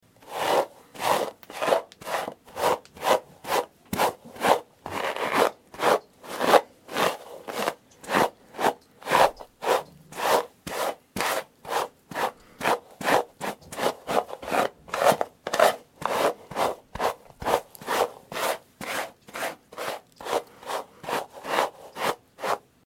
Звуки расчёски
Женщина расчесывает пышную шевелюру в ванной